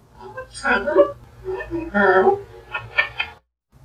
species Ara ararauna commonly known as the Blue and Gold macaw.
Arielle often assembles series of untutored
Arielle's said spontaneously, "I'm a pretty, ... pretty girl!" ... (sound of several kisses)
I_m_a_pretty_girl2.wav